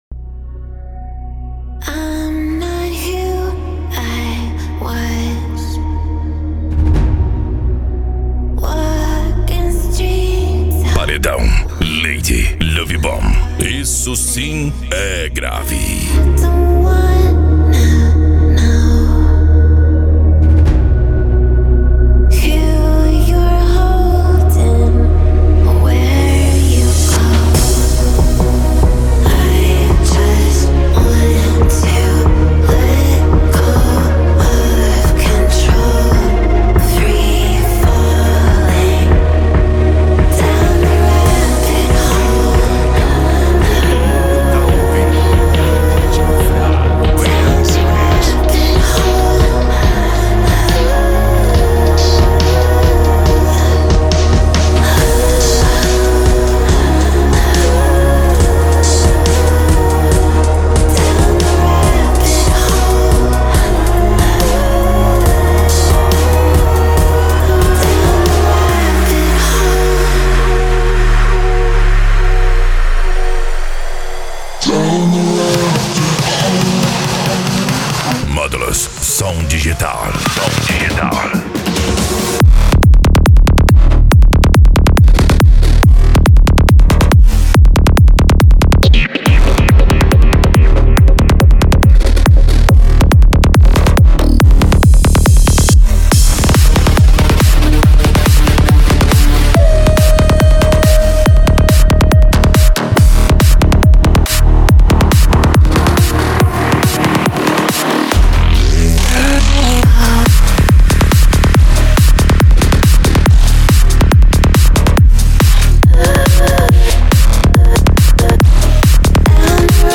Bass
Deep House
Eletronica
Psy Trance